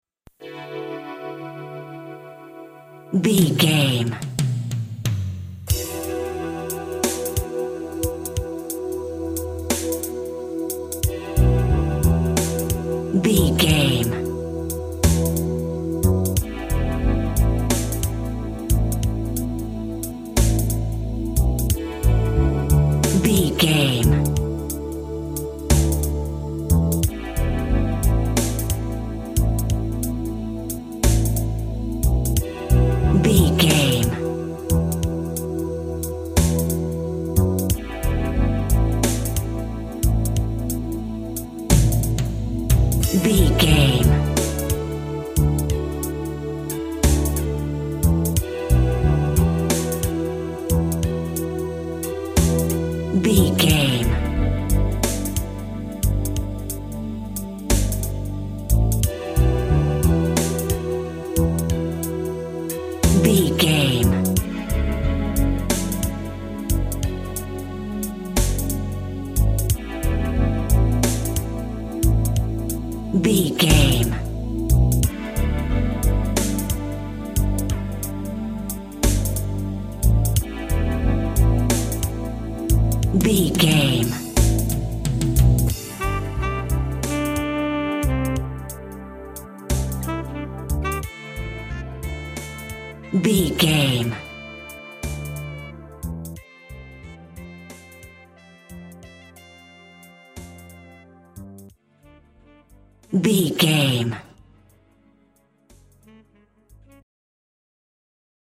Electro 80s House.
Atonal
driving
energetic
futuristic
hypnotic
drum machine
synths